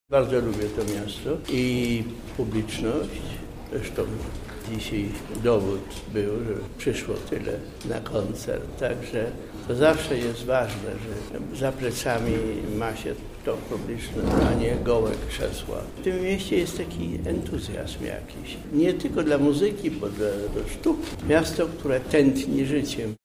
Jak podkreślił Krzysztof Penderecki, to już jego kolejna wizyta w Lublinie: